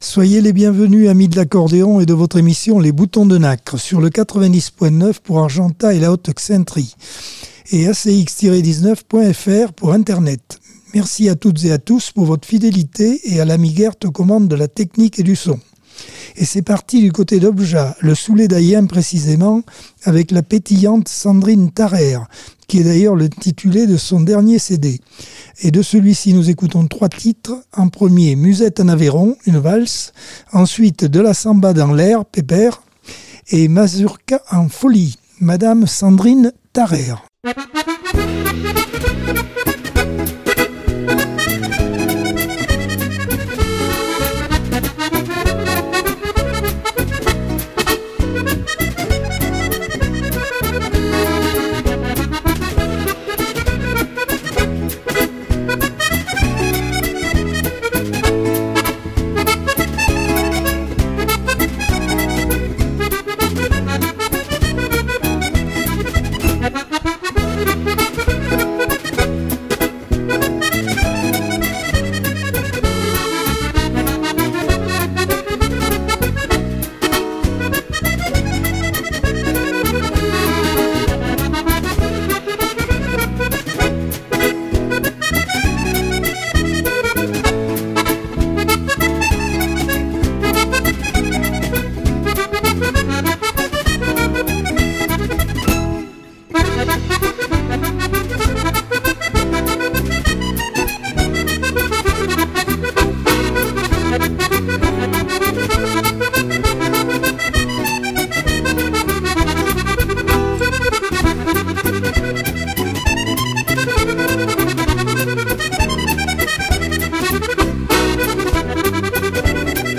Accordeon 2024 sem 37 bloc 1 - Radio ACX